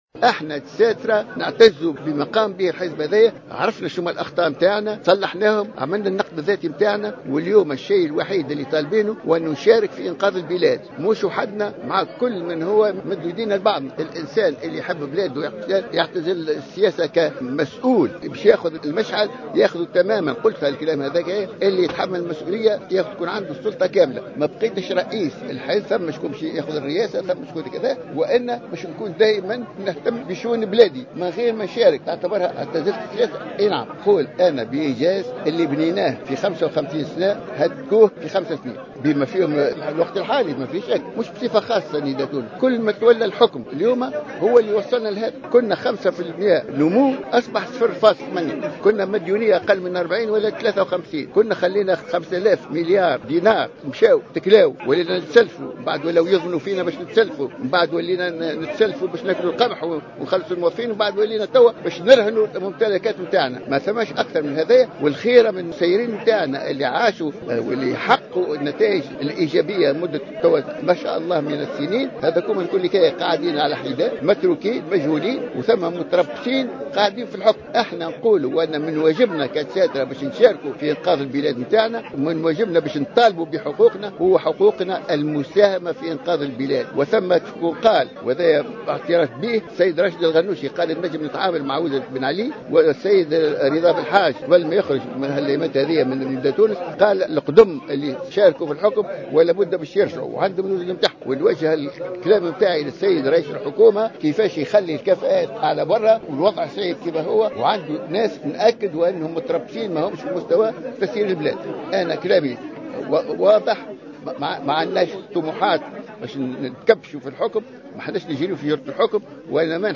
وانتقد القروي خلال ندوة صحفية انعقدت بتونس العاصمة للإعلان عن موعد انعقاد المؤتمر الأول لحزب الحركة الدستورية تردي الأوضاع الاقتصادية والاجتماعية بعد الثورة،مشيرا إلى أن جميع الحكومات المتعاقبة التي تفتقد إلى الكفاءات لم تنجح في إخراج البلاد من الظرف الصعب الذي تمر به.